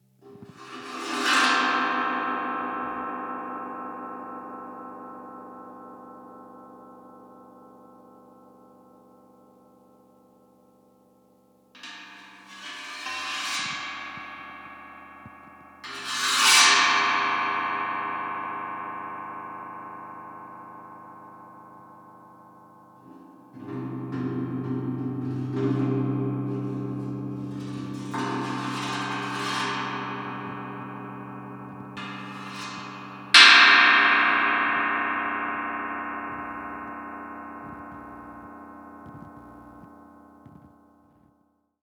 gongs1
cymbal gong metal scrapes sound effect free sound royalty free Music